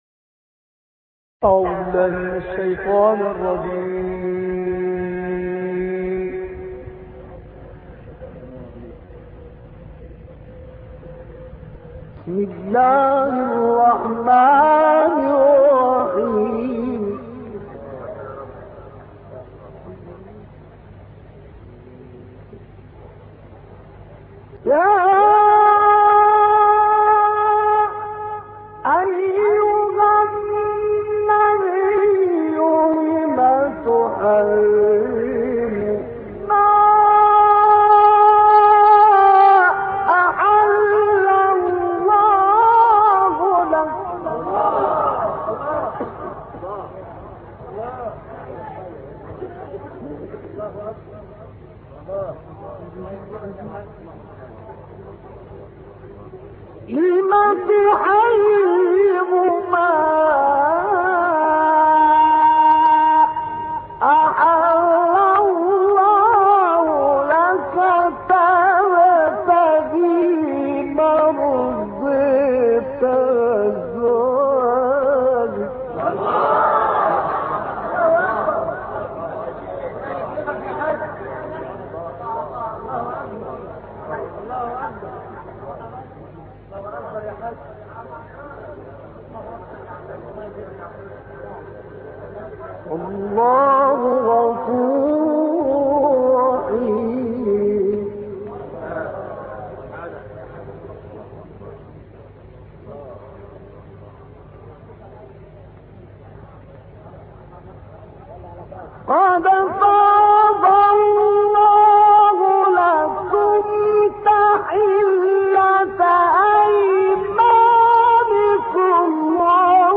البته شیخ حصان در جوانی سبکی نو در تلاوت ابداع کرد و با ارائه روش‌هایی جدید در تنغیم محبوب‌تر شد.
در ادامه تلاوتی شاهکار و شنیدنی از وی شامل آیات ۱ تا ۲۴ سوره مبارکه حاقه تقدیم می‌شود.